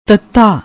twenty first letter of Gurmukhi script representing voiceless unaspirated dental plosive [t]